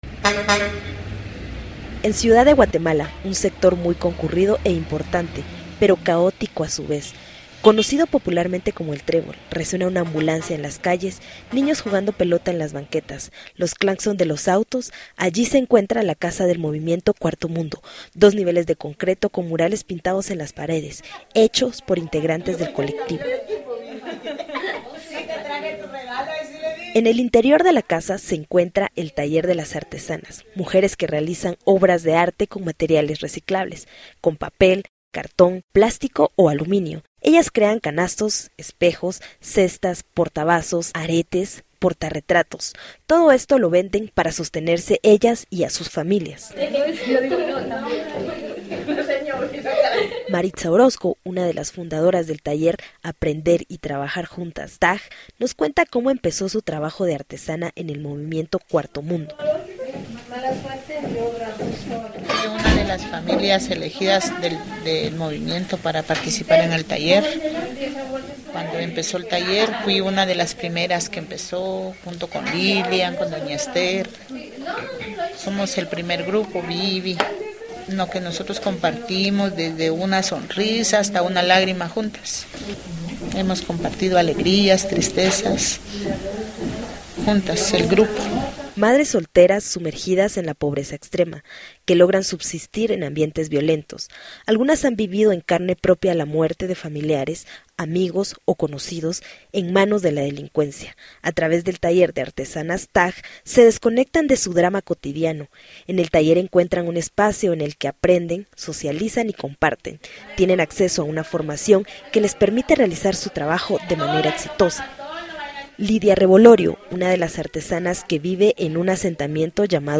En El Trébol resuena una ambulancia en las calles, niños juegan a la pelota en las banquetas, y se escuchan los cláxones de los autos.